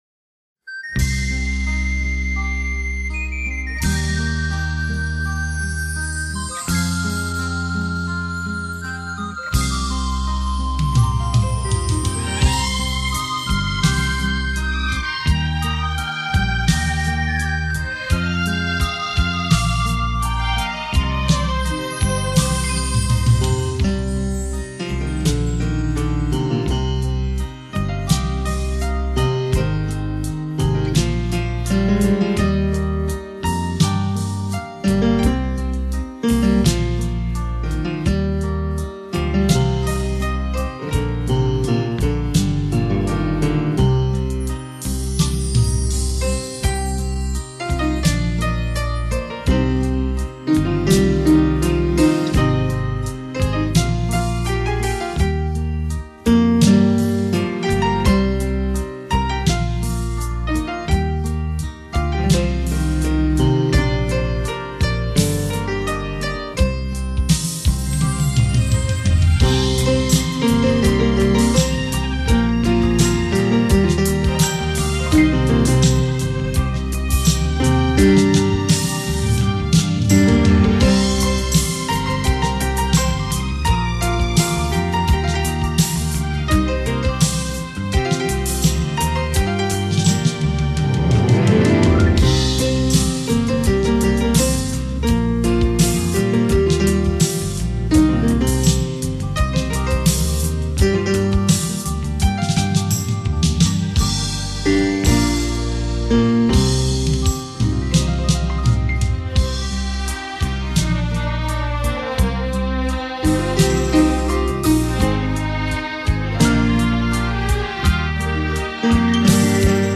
piano,钢琴